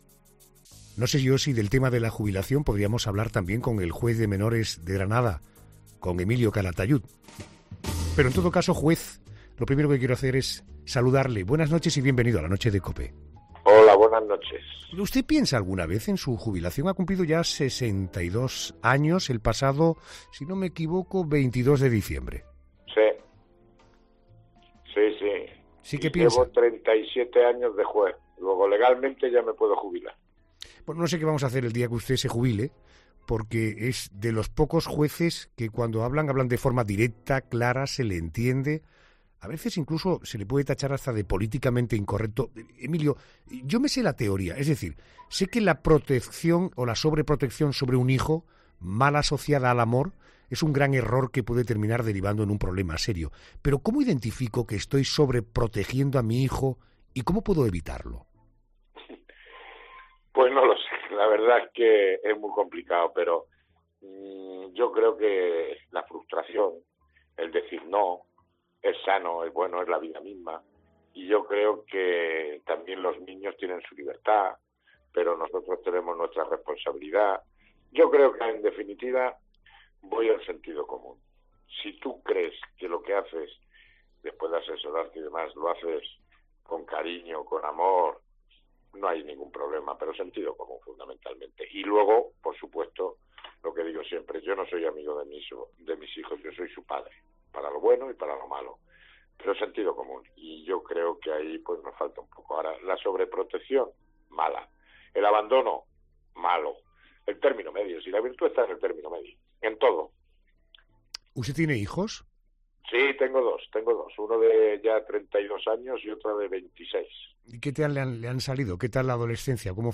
El juez de menores de Granada, Emilio Calatayud, ha denunciado en 'La Noche' de COPE